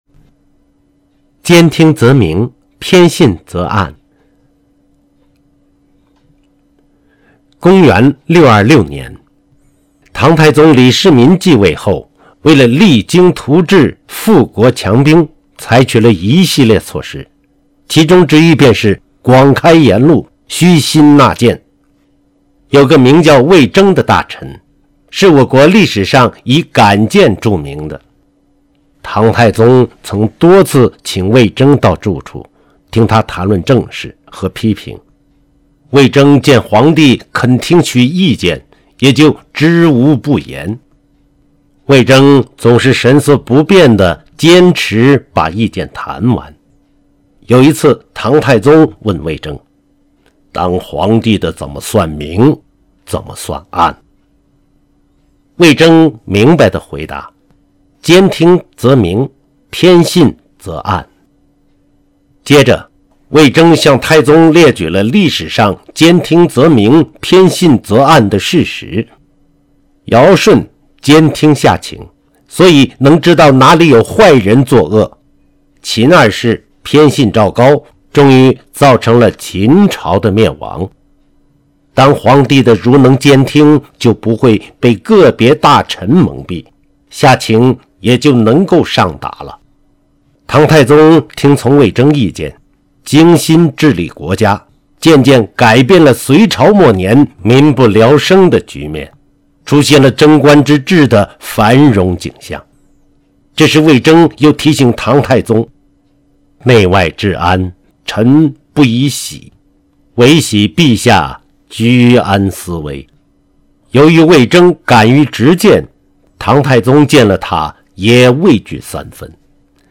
成语故事